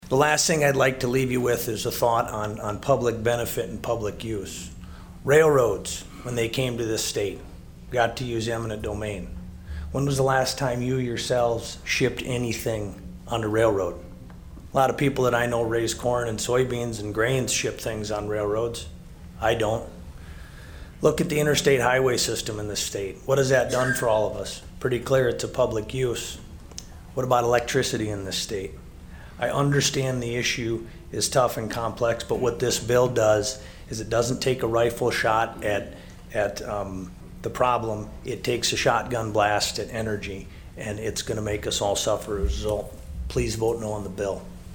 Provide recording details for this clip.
PIERRE, S.D.(HubCityRadio)- The South Dakota Senate State Affairs Committee heard testimony on SB49.